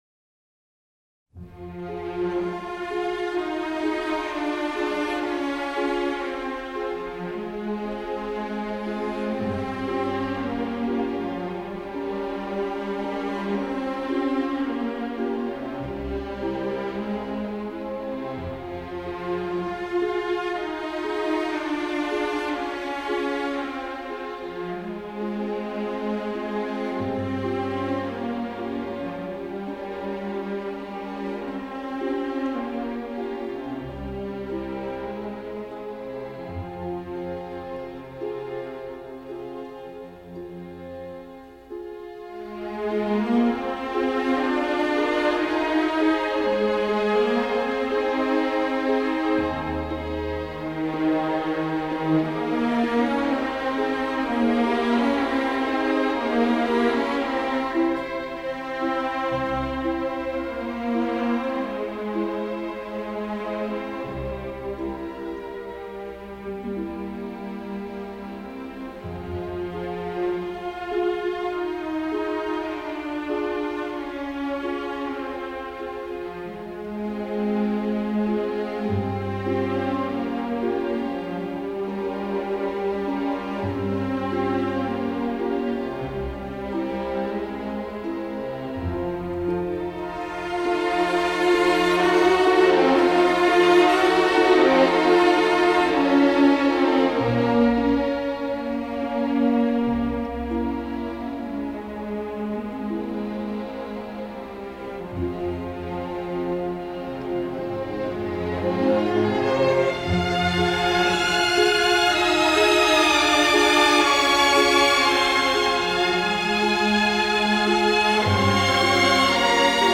Джозеф Каллейа. Массне. Ария Вертера из оперы "Вертер"